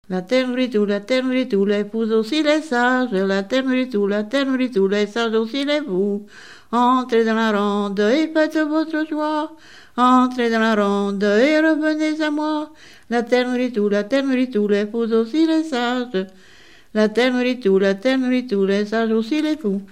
Note ronde
Rondes enfantines à baisers ou mariages
Pièce musicale inédite